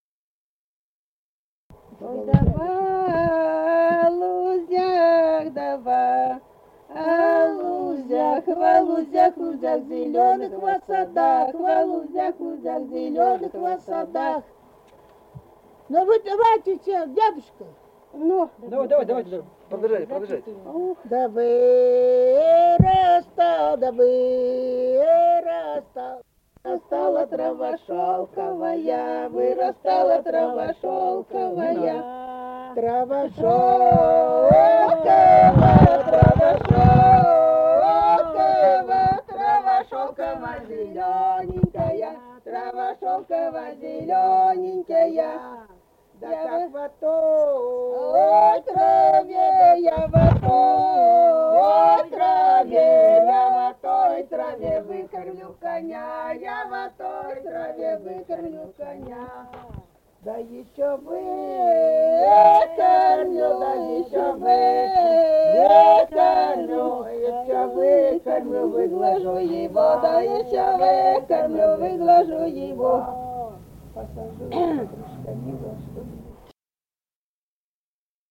Русские песни Алтайского Беловодья 2 «Ох, да во лузях», хороводная / «лужошная», круговая.
Республика Казахстан, Восточно-Казахстанская обл., Катон-Карагайский р-н, с. Коробиха, июль 1978.